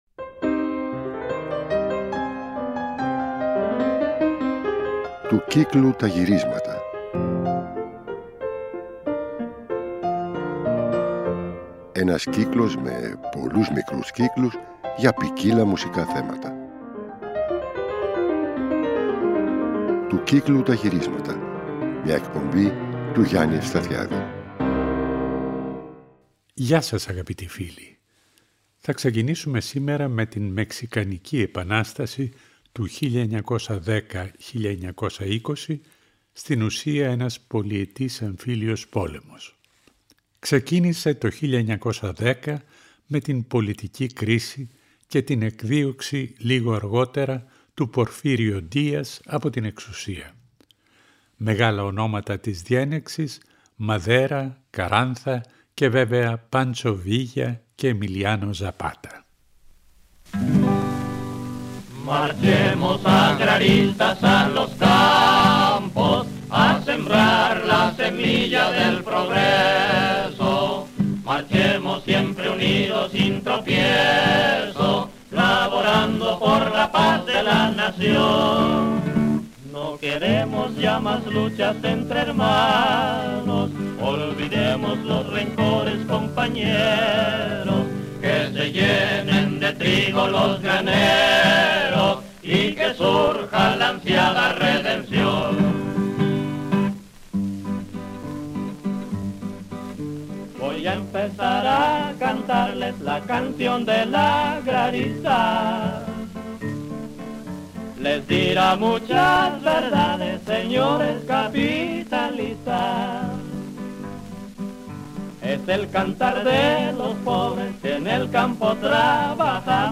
Αφιέρωμα στα Εμβατήρια (19ο Μέρος)
Στο β’ μέρος υπάρχει μια επιλογή τραγουδιών και εμβατηρίων από την Παλαιστίνη.